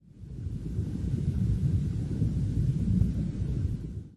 Index of /Sounds/Thunder Sounds
rollingthunder.wav